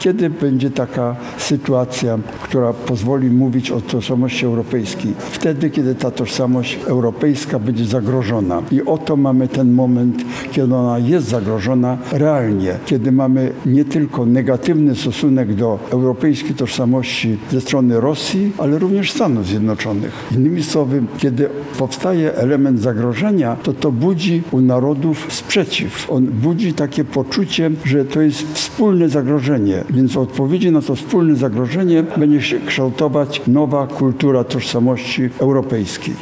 O poszukiwaniu nowej strategii bezpieczeństwa Polski w Europie mówił na Katolickim Uniwersytecie Lubelskim były minister spraw zagranicznych, badacz stosunków międzynarodowych, prof. dr hab. Adam Rotfeld.